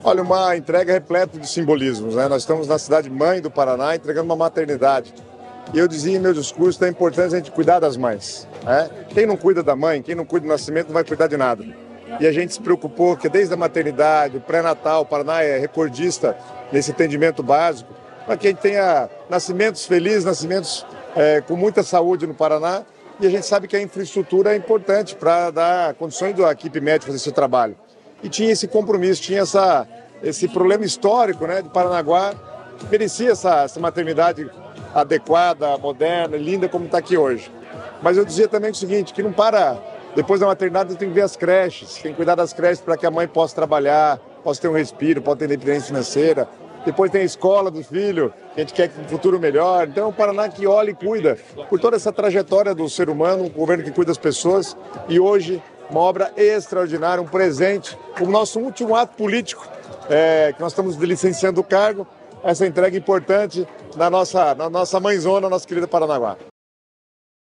Sonora do secretário das Cidades, Guto Silva, sobre a inauguração da nova Maternidade de Paranaguá